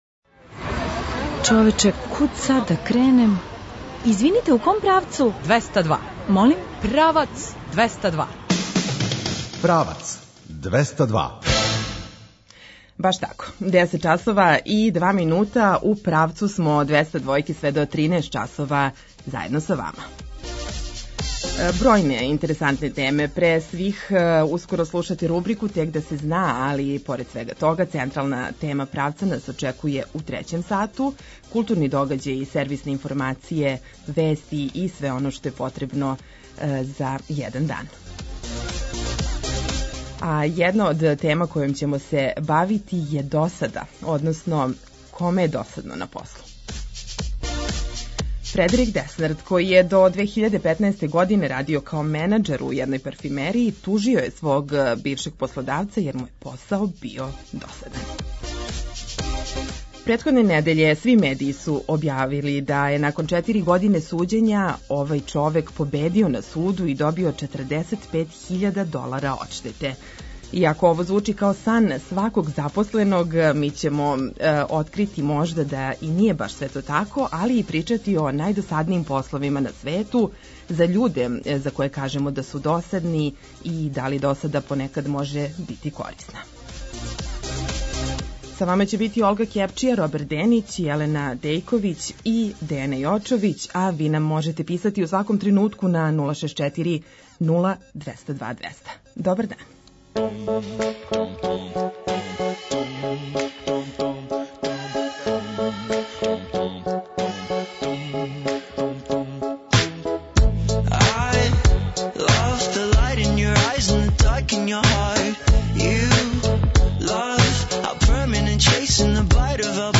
У рубрици Тек да се зна ћемо поменути најнеобичније дрвеће на свету, разговараћемо о културним догађајима, од репортерa сазнати шта је то ново у граду, a свe тo уз сјајну музику.